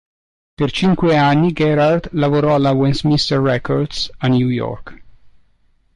cìn‧que
/ˈt͡ʃin.kwe/